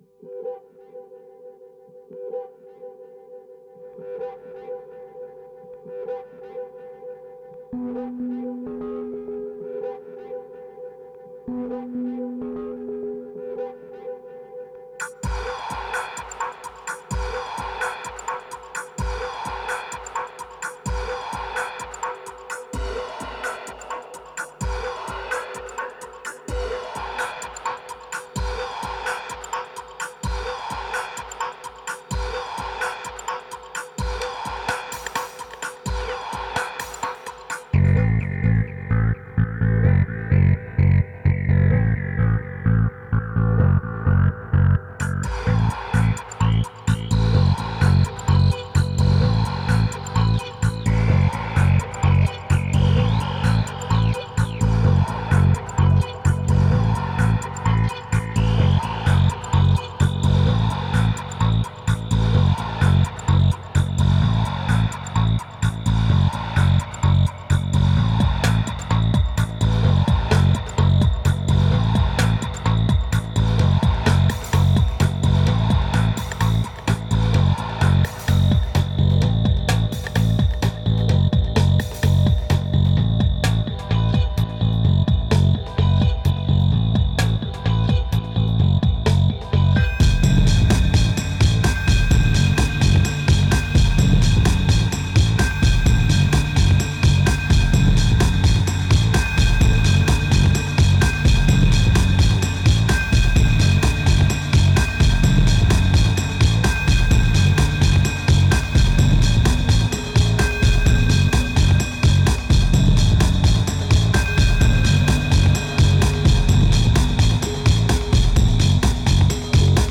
1955📈 - 22%🤔 - 128BPM🔊 - 2011-09-09📅 - -94🌟